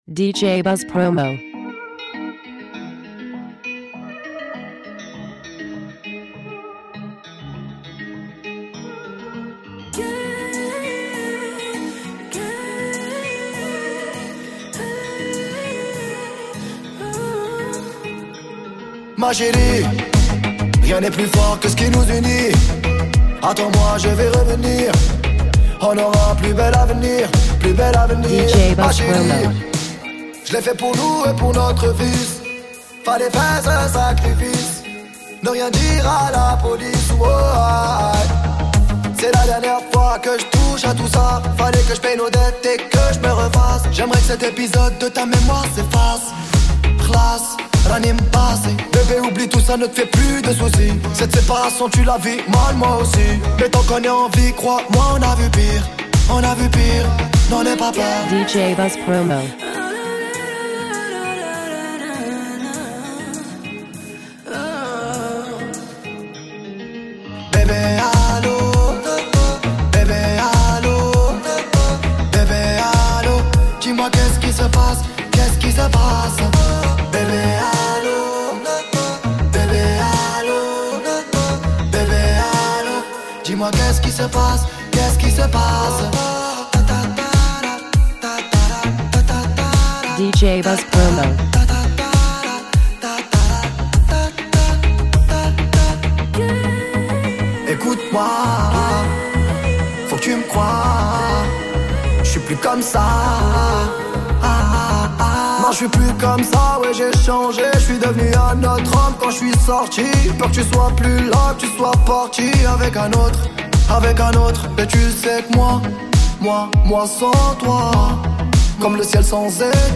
Between actual sounds and oriental influences
influenced by Raï and Rap